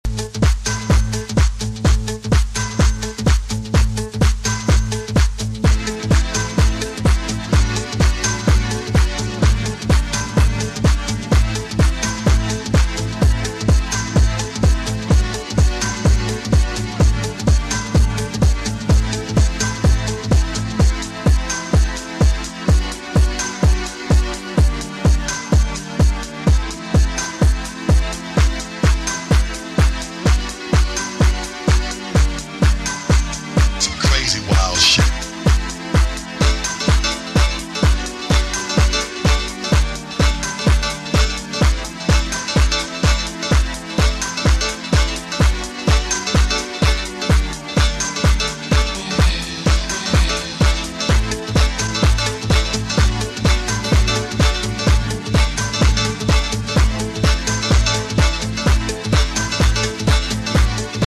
ホーム > HOUSE/BROKEN BEAT > V.A